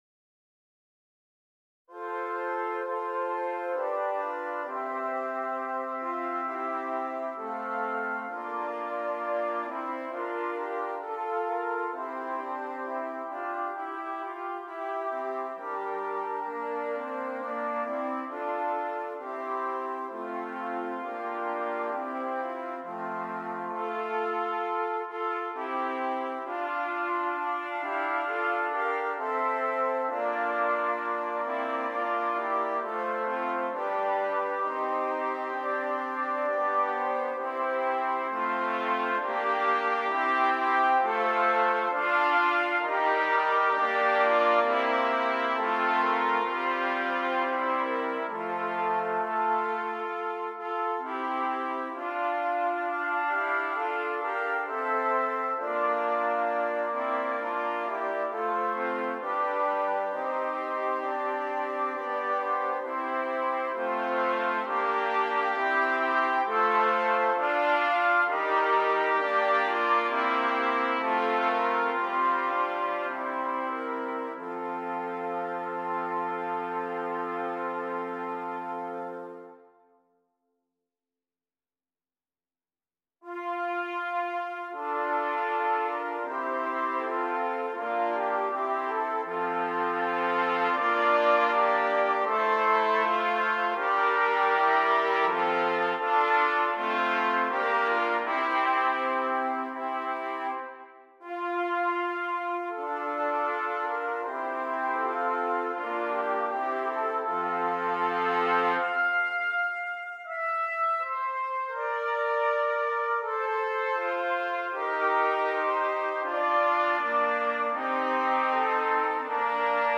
4 Trumpets